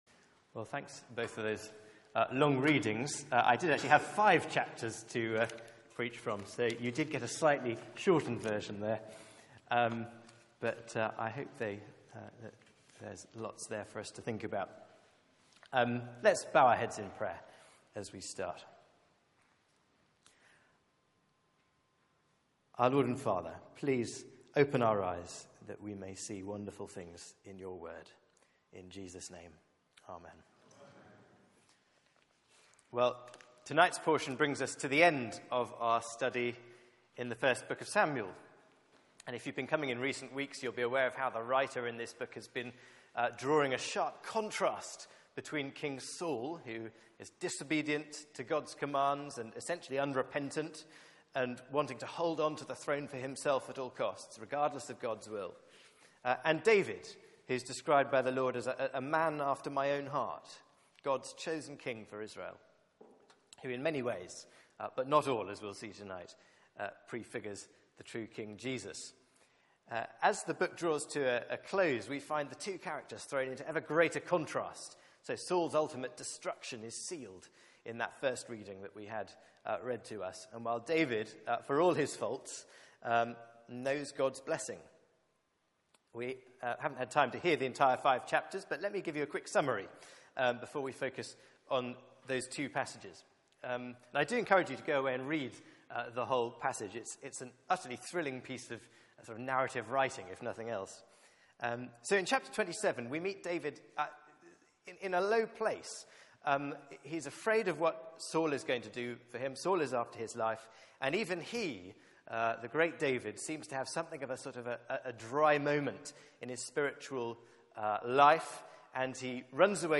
Media for 6:30pm Service on Sun 20th Jul 2014 18:30 Speaker
Choose your king Theme: Honour or judgment? Sermon Search the media library There are recordings here going back several years.